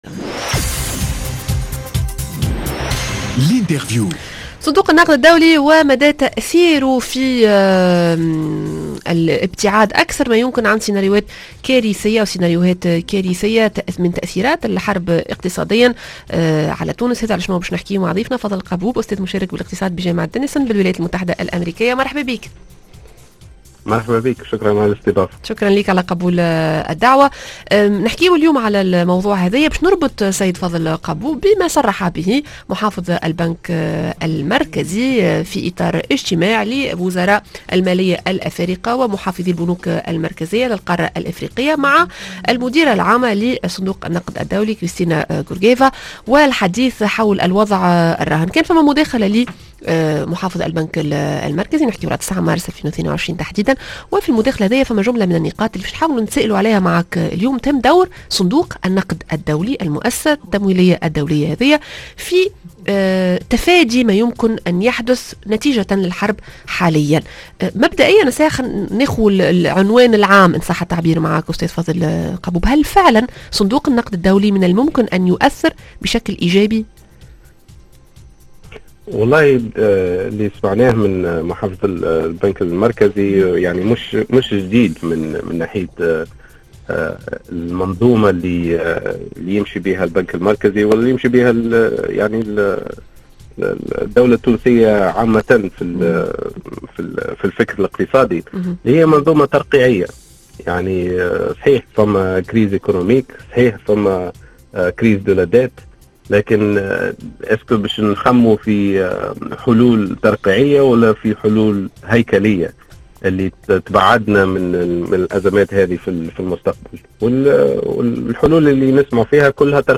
L'interview: هل صندوق النقد الدولي ينجّم يتدخّل باش ينقّص من تأثير الحرب اقتصاديا؟